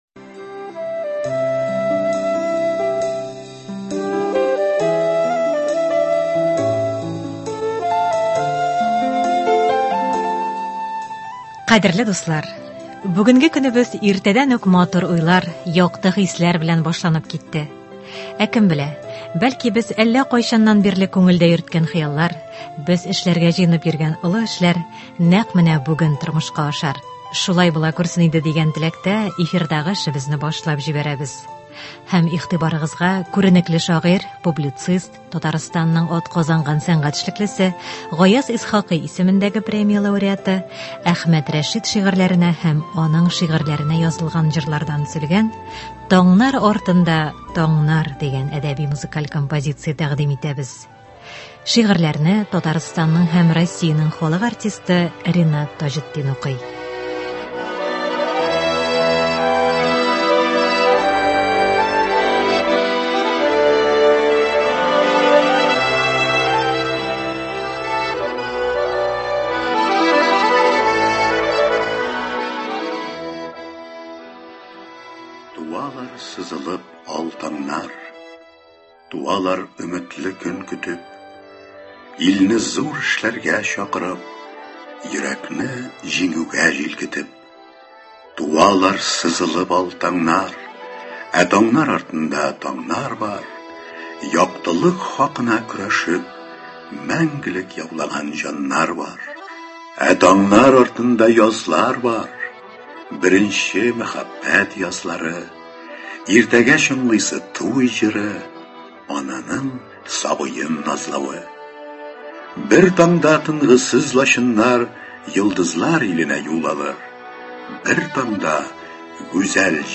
Әдәби-музыкаль композиция (20.01.24)